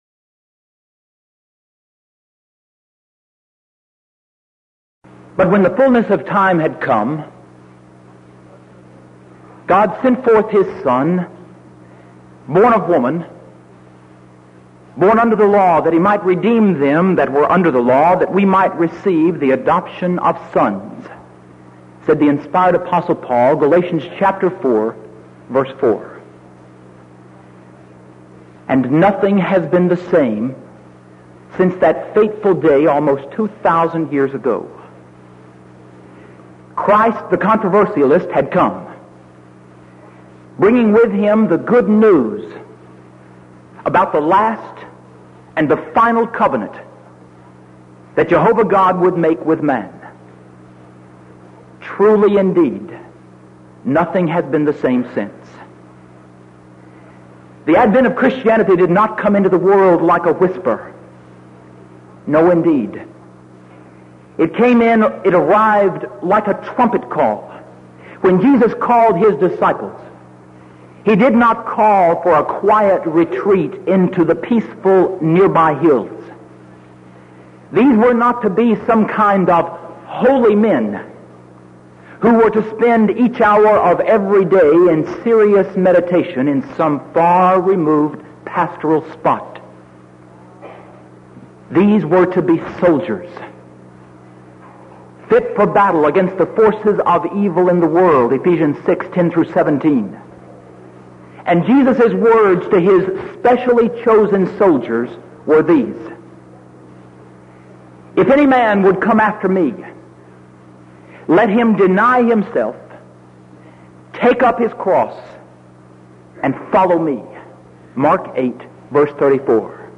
Series: Denton Lectures